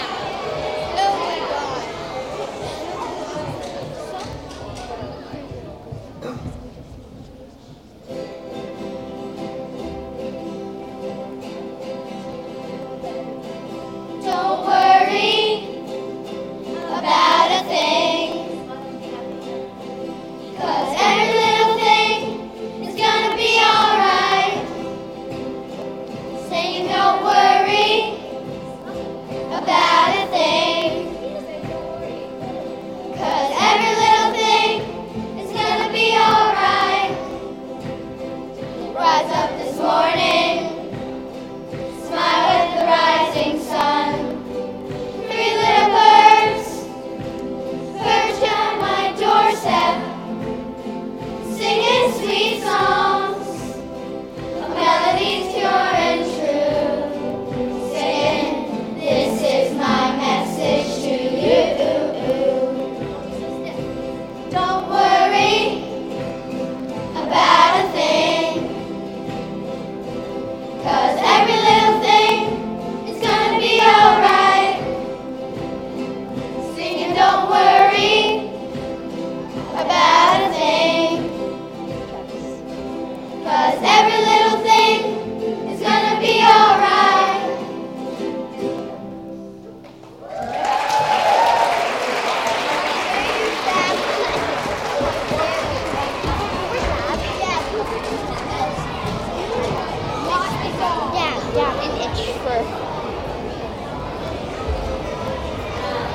Our fourth-eighth grade musicians are truly enjoying learning the new ukuleles!
Eighth graders recently accompanied the Junior Chorus in a rendition of Three Little Birds at assembly an